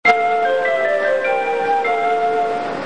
チャイム４は高速バスのチャイムで、以前は一般路線バスでも使用されてました。